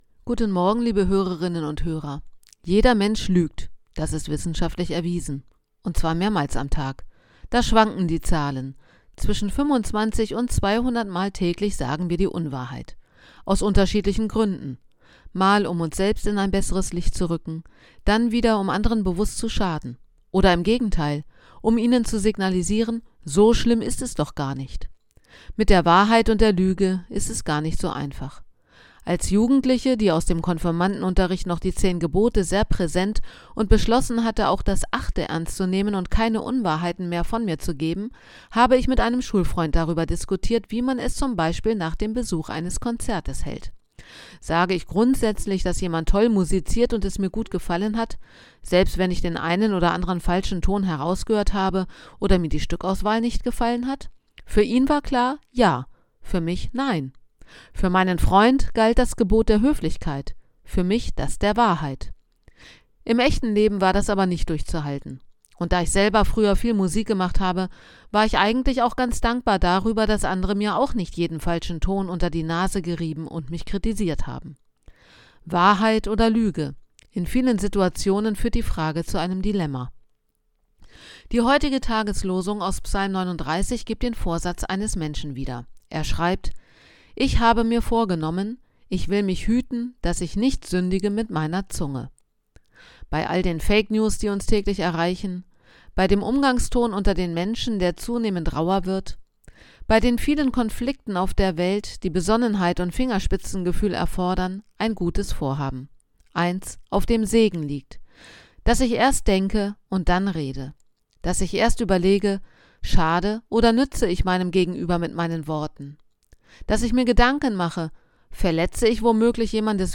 Radioandacht vom 8. August